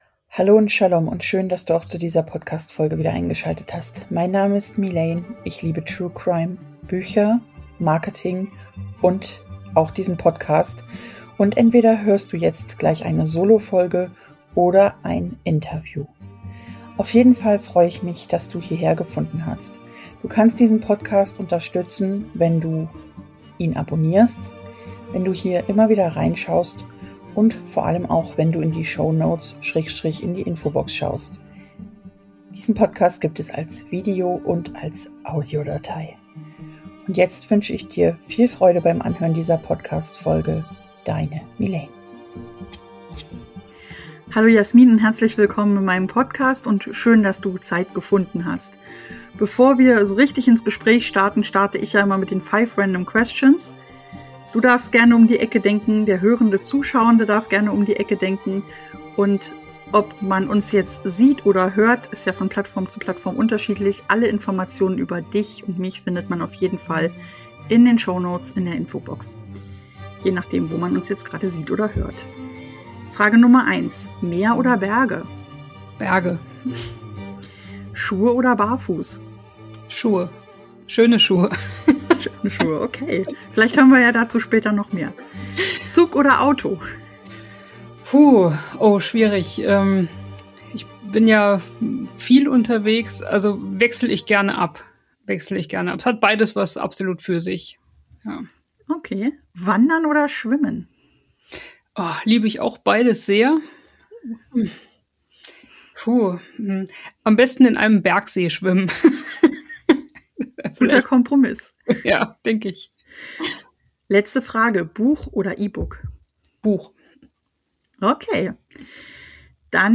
Im Interview haben wir über True Crimes im Marketing mal ein wenig abseits von Social Media gesprochen. Was kann im Business alles schief laufen? Neustart statt Dauerfrust oder doch das Ende des Traumes?